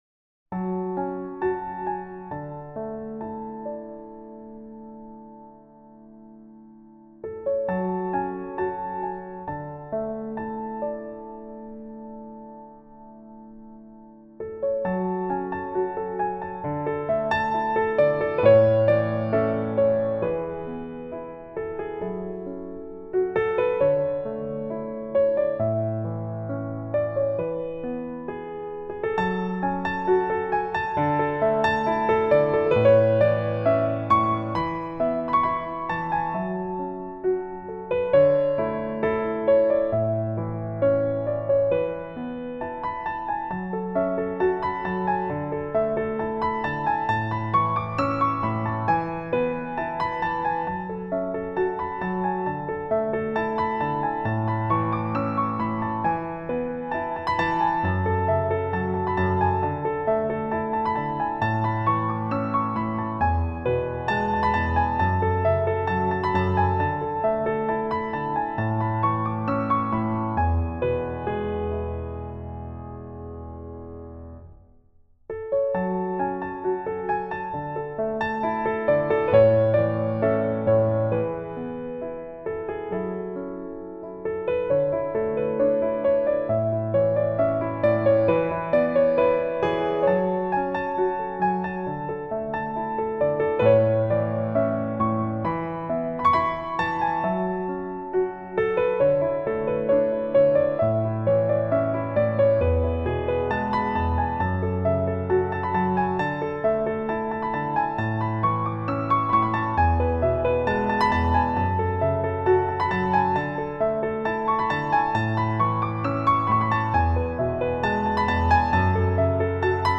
Piano Demos